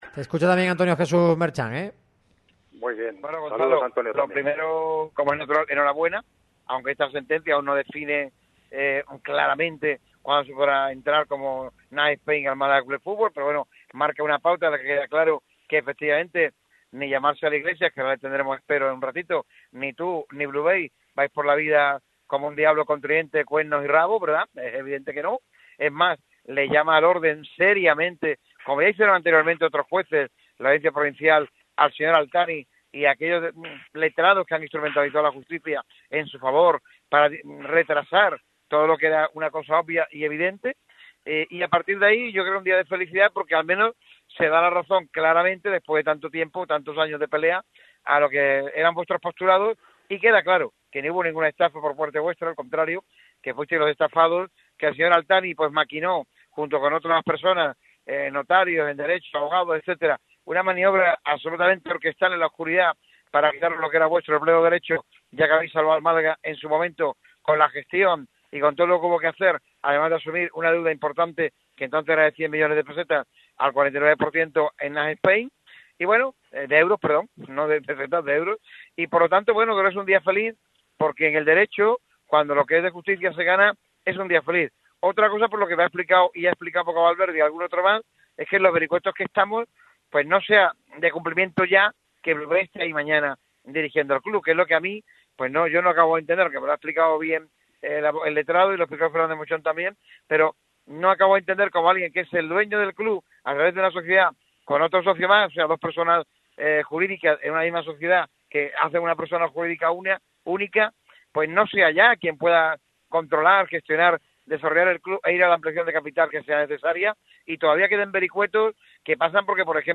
ha pasado por los micrófonos de Radio MARCA Málaga para comentar el futuro del Málaga CF.